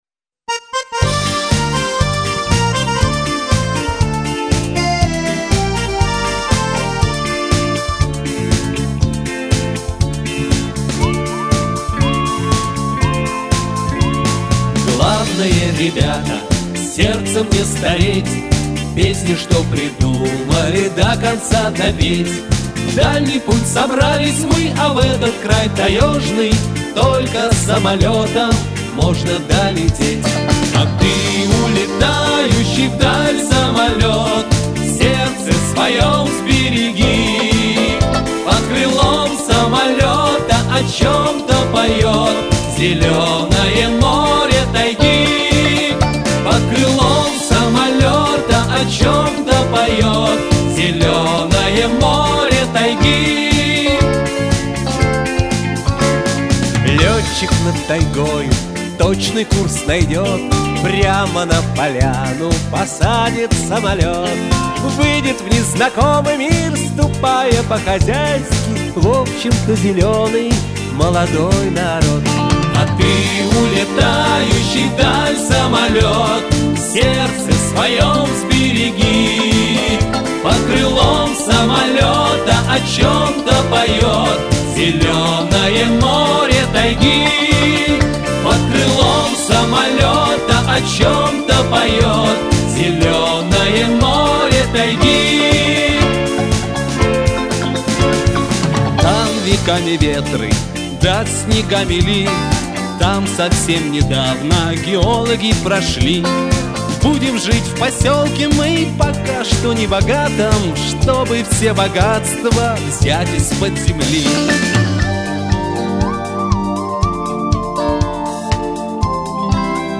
Концерт закончился песней